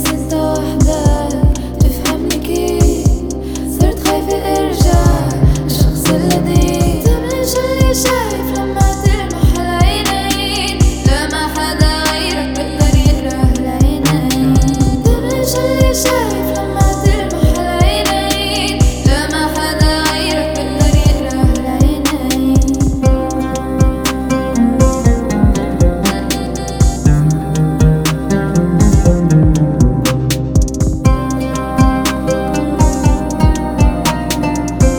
2022-03-05 Жанр: Поп музыка Длительность